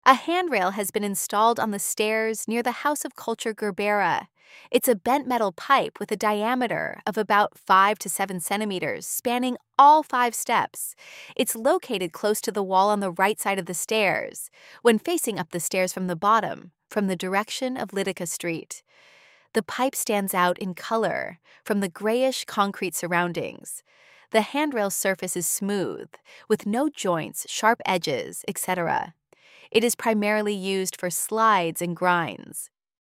AUDIODESCRIPTION HANDTRAIL